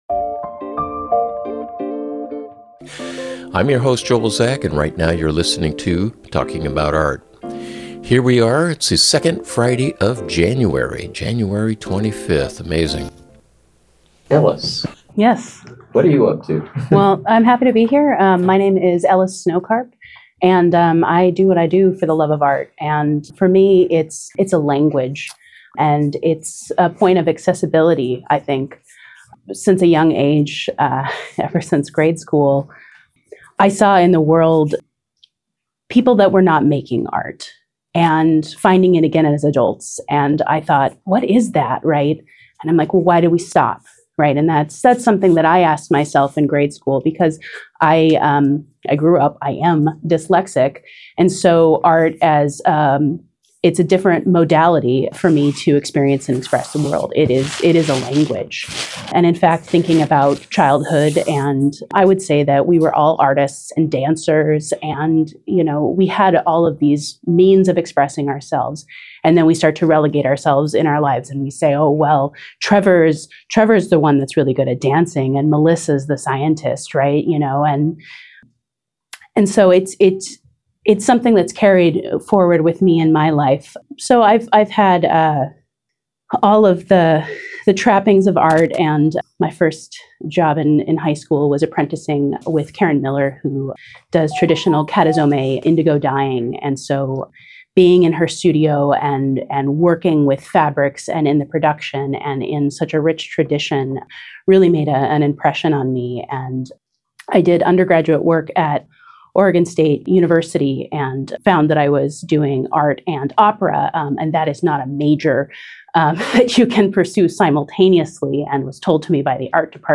Artist interview